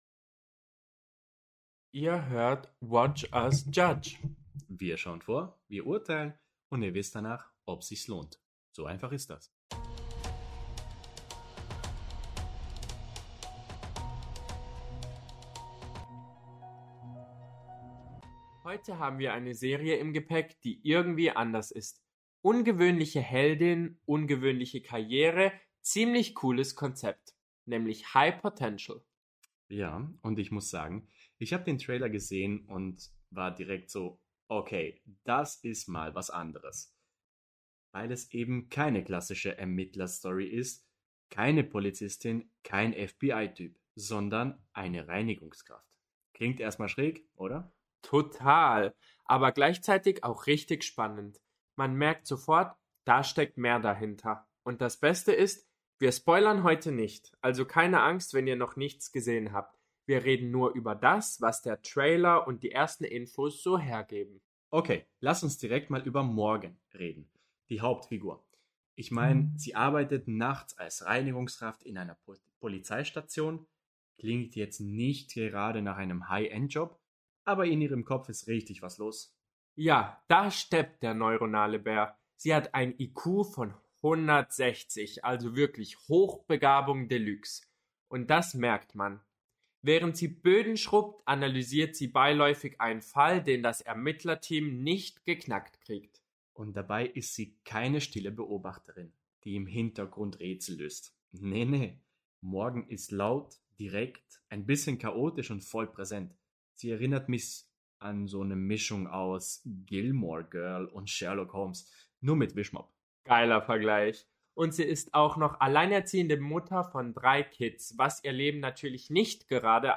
🎙 Ein Paar, eine Watchlist – und mehr Meinung als das Internet braucht.